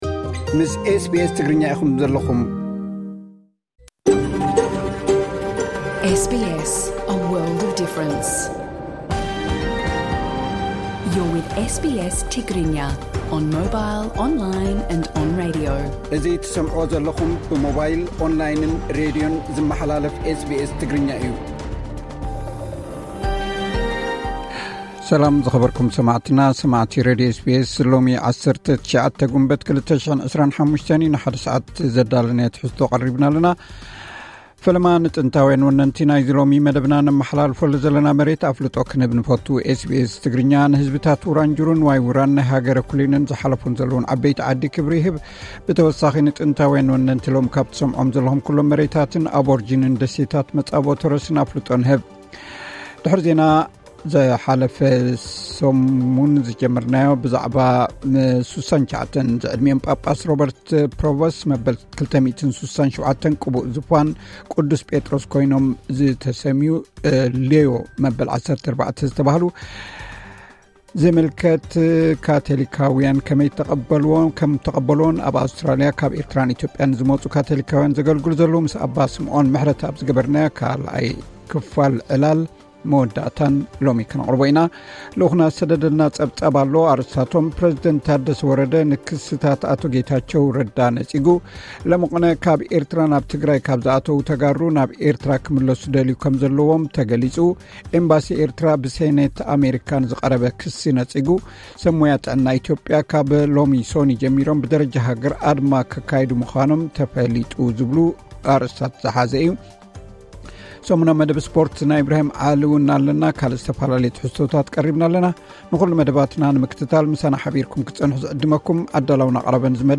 ቐጥታ ምሉእ ትሕዝቶ ኤስ ቢ ኤስ ትግርኛ (19 ግንቦት 2025)